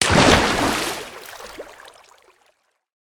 heavy_splash.ogg